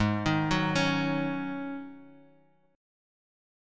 G#7sus4 chord